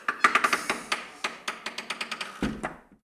Abrir o cerrar una puerta de madera pequeña
puerta
madera
Sonidos: Hogar